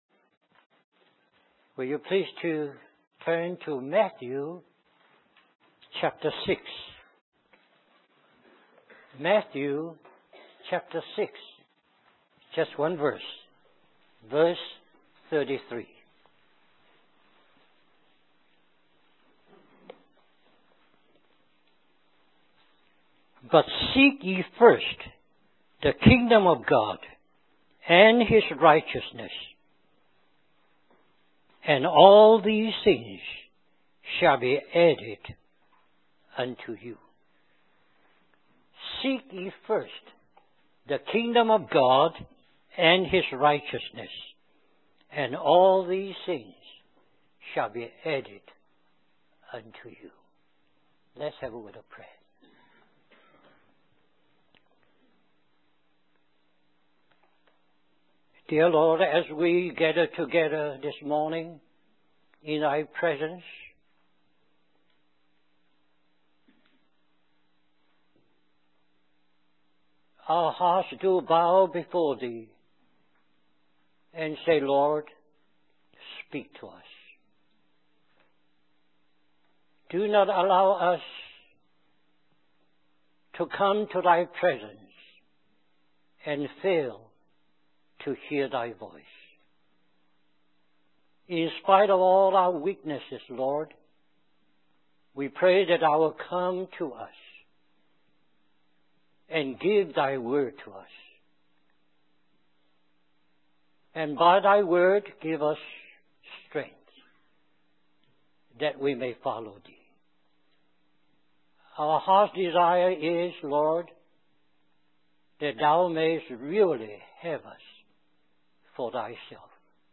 In this sermon, the speaker emphasizes the importance of prioritizing the kingdom of God in our lives. He uses the example of the children of Israel in Egypt, who were kept busy with hard labor to prevent them from focusing on spiritual matters. The speaker argues that this strategy of the enemy is still prevalent today, as many Christians prioritize worldly concerns over seeking the kingdom of God.